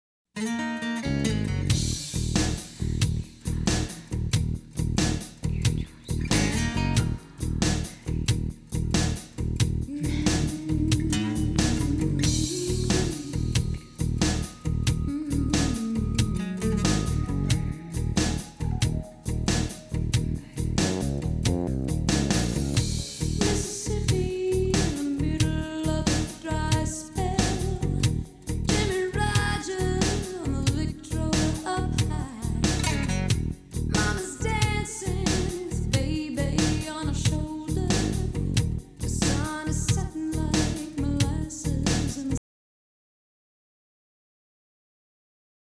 Gebratzel nach Systemupdate
Einmal Referenzsong (allerdings nur der linke Kanal und mono) einmal ohne DI via LineIn und einmal mit DI über MicPre